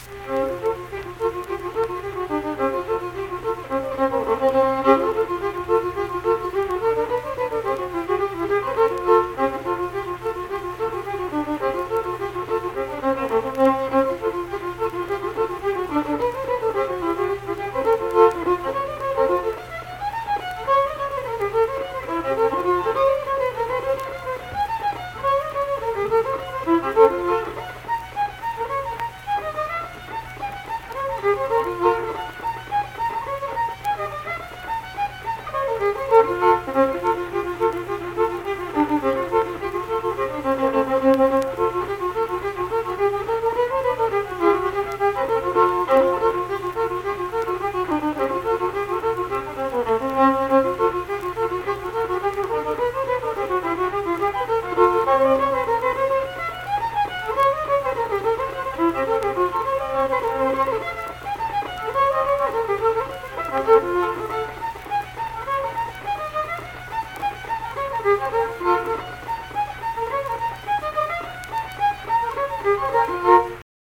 Unaccompanied fiddle music
Instrumental Music
Fiddle
Saint Marys (W. Va.), Pleasants County (W. Va.)